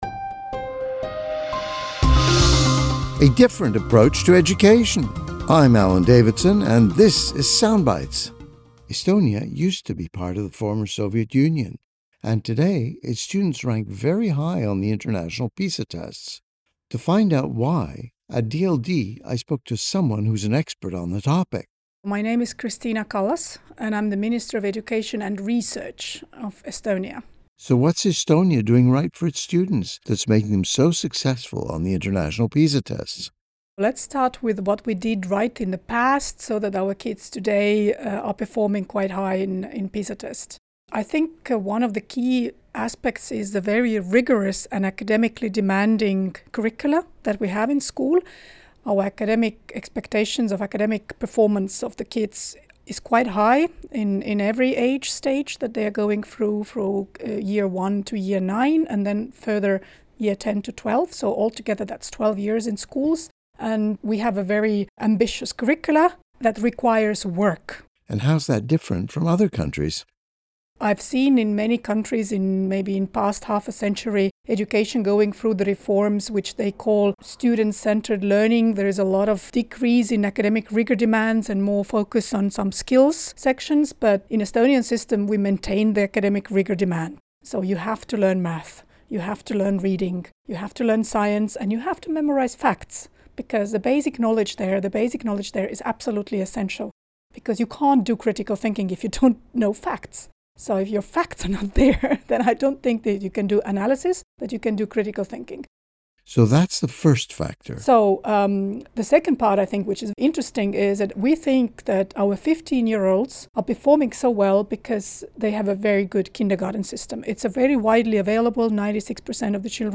1824F_Extended_Interview_with_Kristina_Kallas.mp3